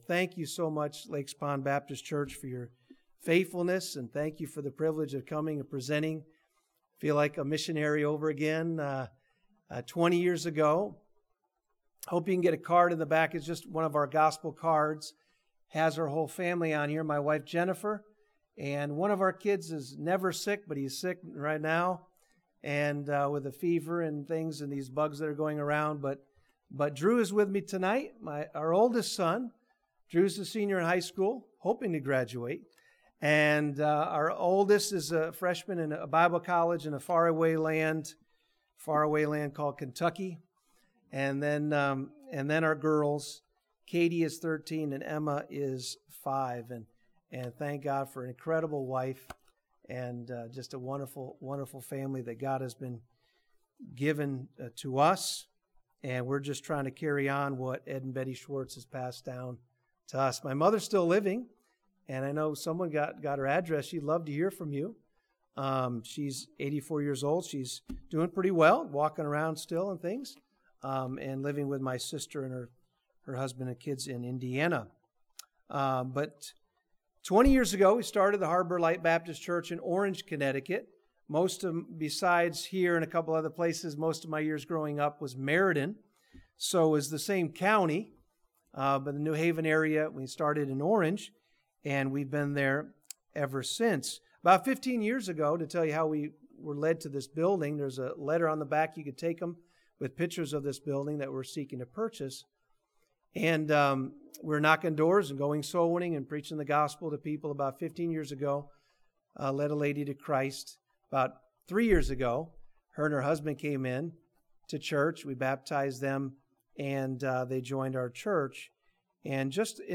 This sermon challenges the believer to have vertical vision - to not look down or around but to look up to Jesus.